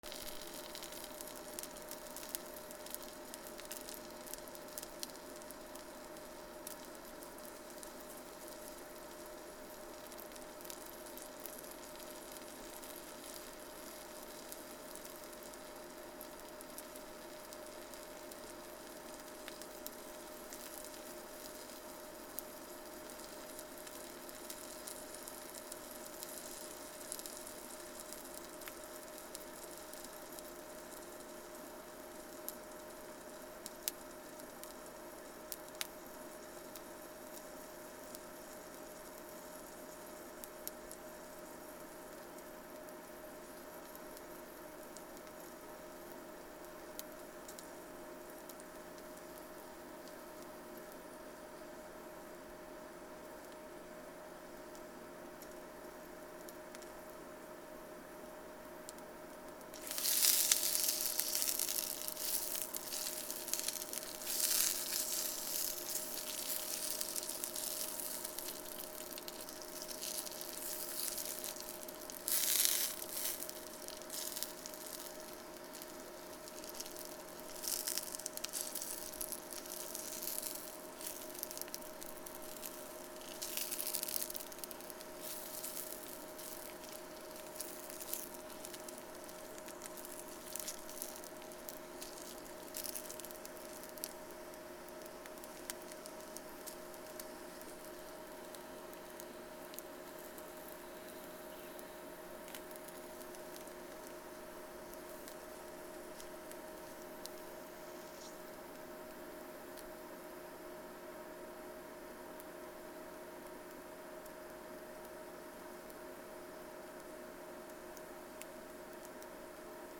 ウインナーを焼く(IHヒーター)